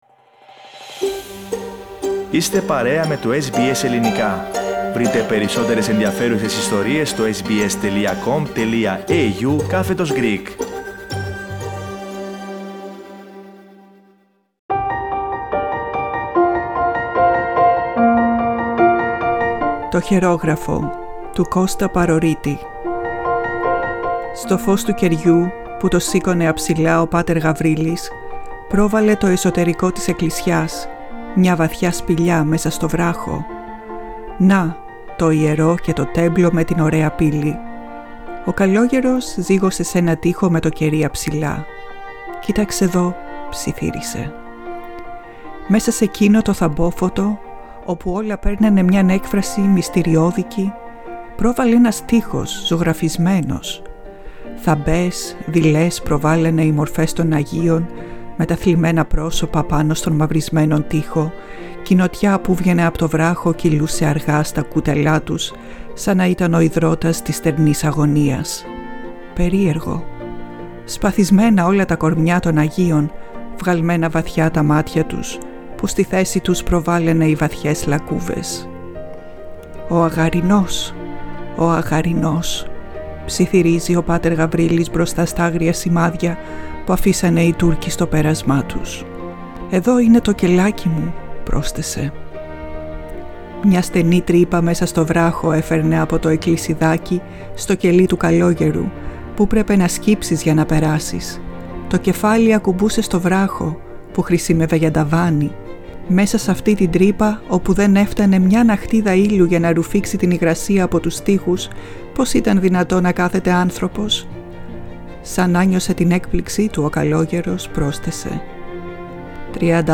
Produced and narrated